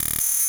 sci-fi_code_fail_03.wav